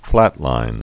(flătlīn)